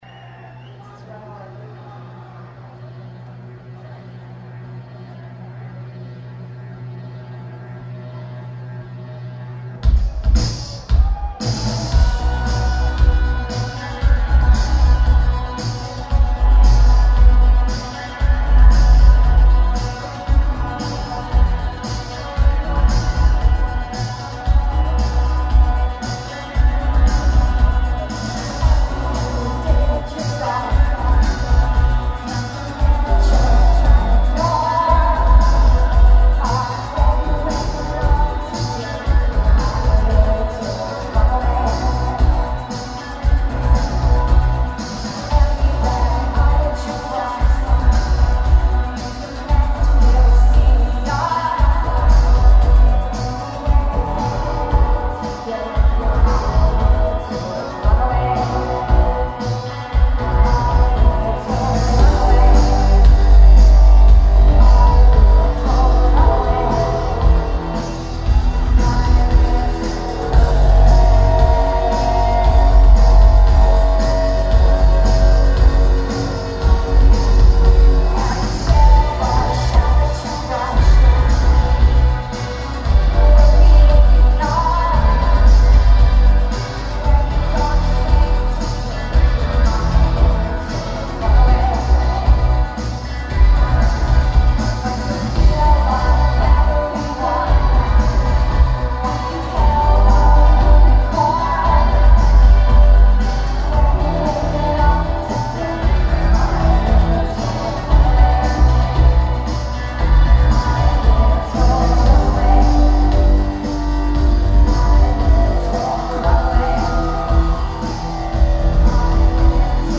detached synth wave